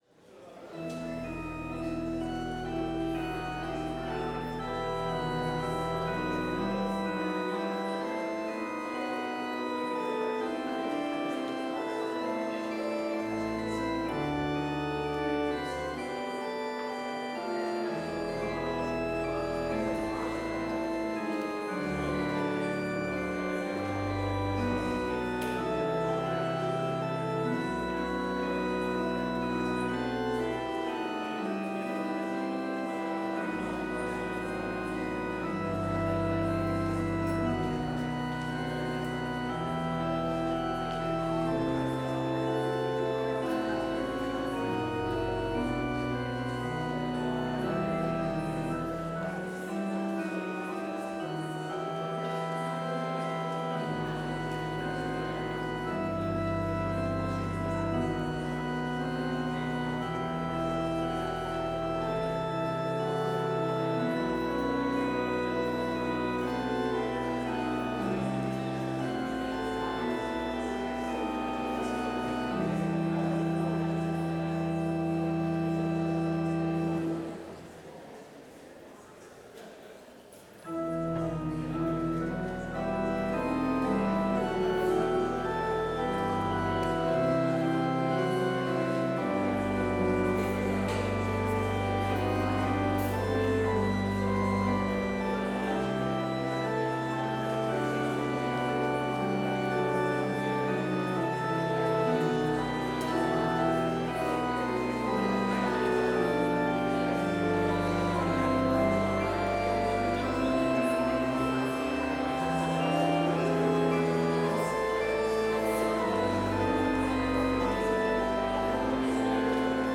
Complete service audio for Chapel - Tuesday, September 2, 2025